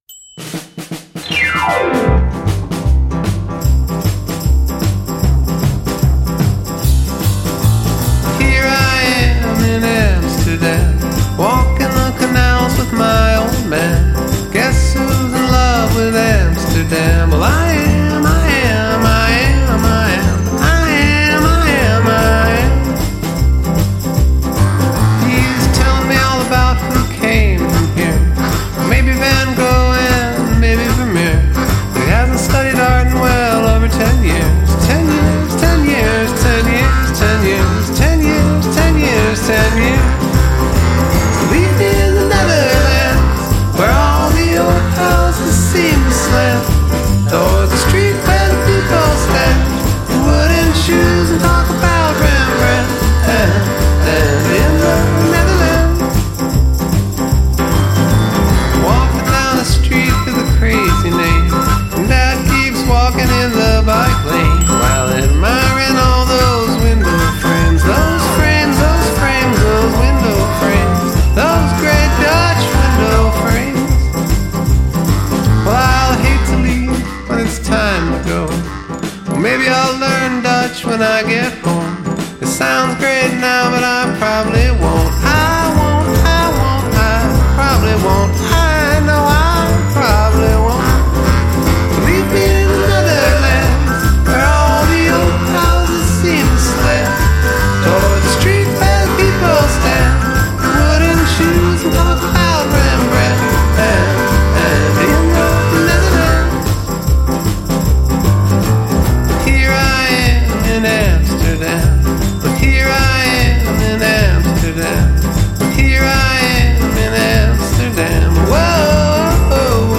has an easy, whimsical, tongue-in-cheek way with a song